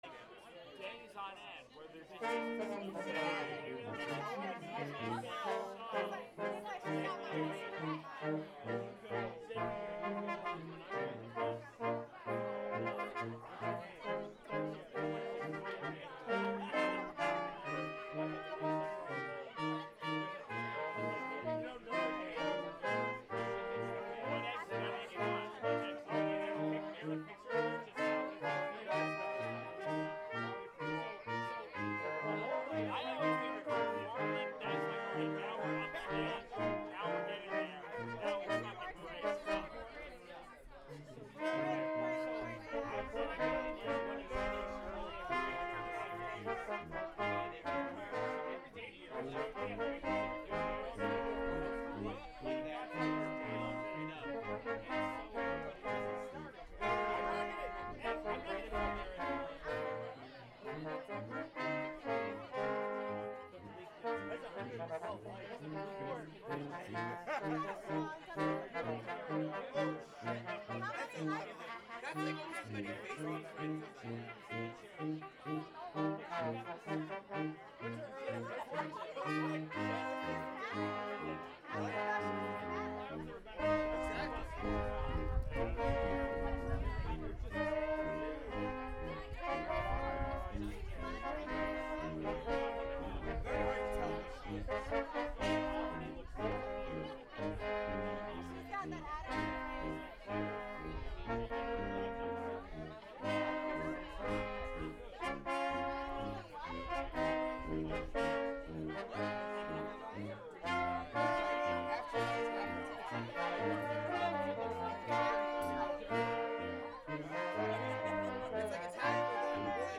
A Brass Quintet from Brooklyn
The Brass Monkeys play light classical music and seasonal favorites across the New York City area.
Take a listen to some of our songs (recorded live at Oak Wine Bar in 2015) and click the button at the bottom to see how we can work with you:
Und da wollen wir noch einmal (Polka)